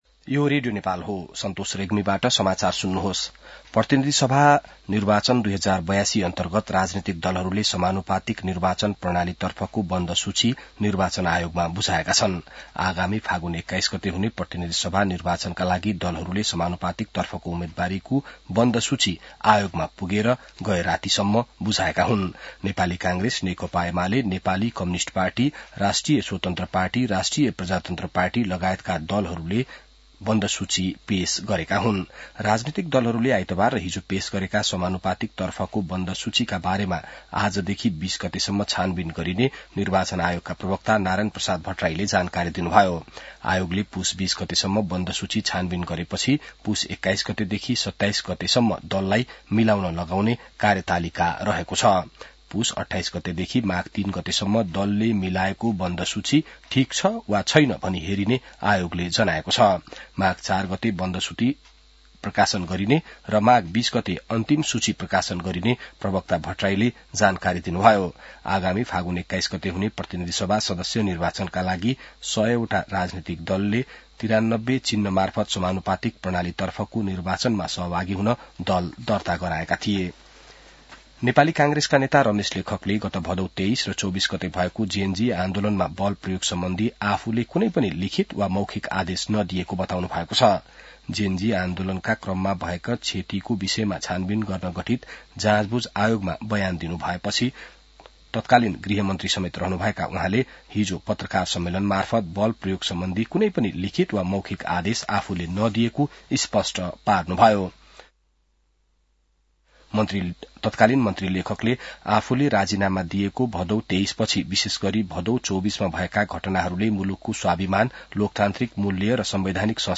बिहान ६ बजेको नेपाली समाचार : १५ पुष , २०८२